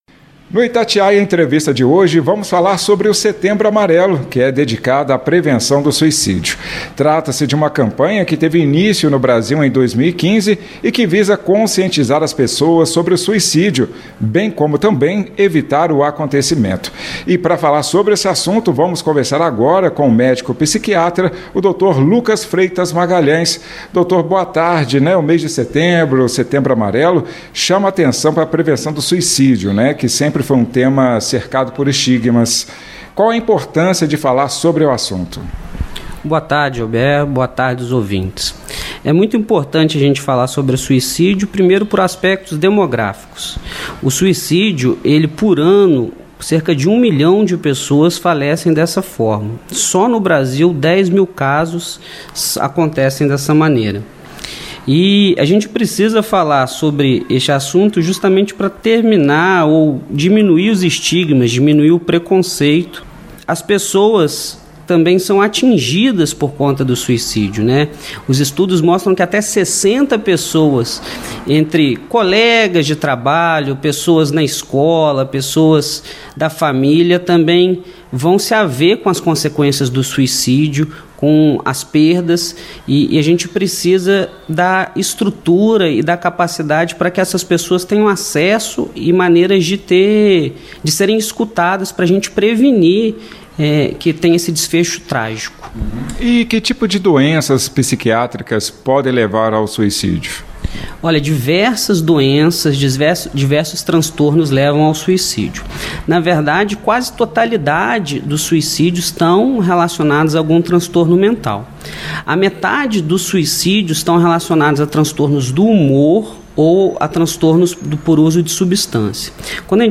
ok-ITATIAIA-ENTREVISTA-SETEMBRO-AMARELO-suicidio.mp3